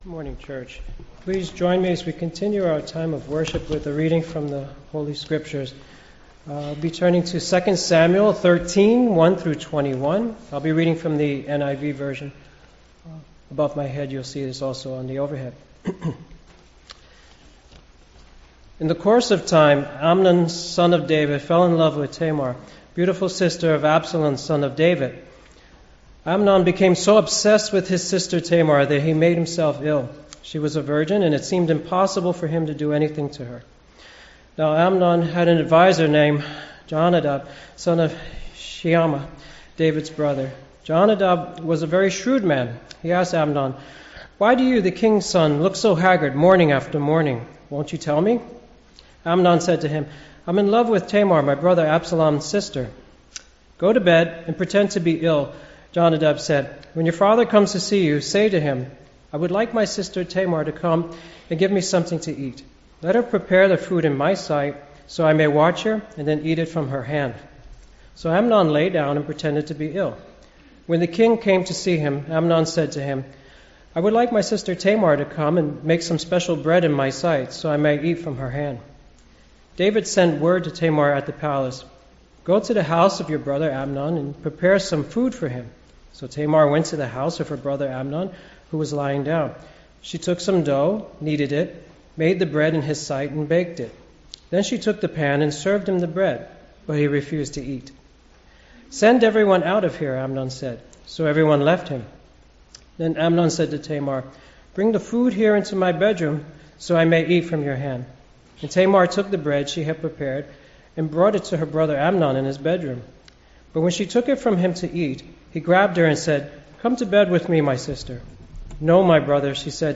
English Sermons | First Baptist Church of Flushing
Celebrating SANCTITY OF HUMAN LIFE SUNDAY